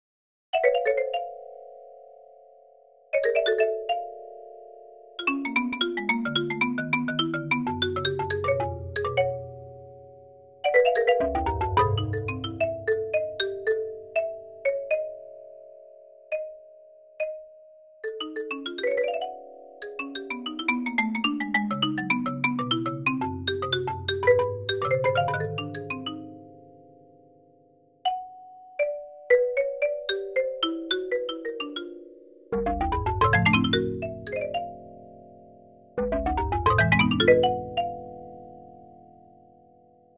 Below is a piano-roll representation of the music we get: box 5 adds a program/bank change at the beginning of the music specifying a nice xylophone soundfont.
A lot of notes are not actually played by the soundfont (being out of its range) so the composition is not as dense as it may seem from the above display.
xylo.ogg